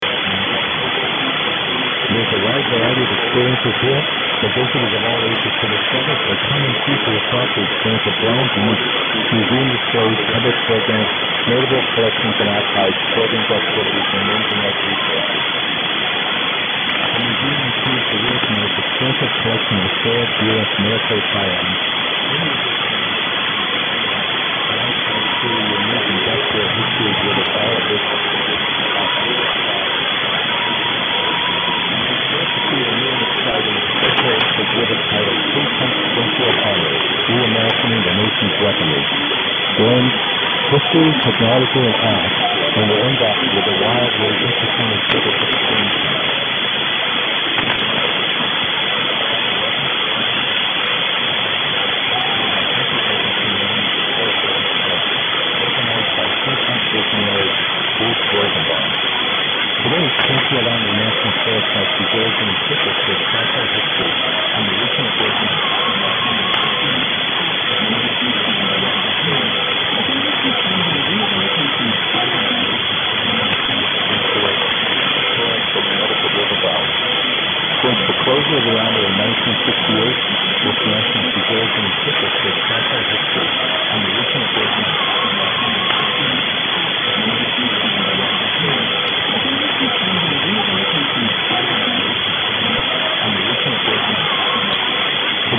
But the other station with the YL was still there, but not so dominant today.
It also pops up a few times in and out with the MA station.
140411_0457_1710_yl_unid_web_address_mix_ma_armory.mp3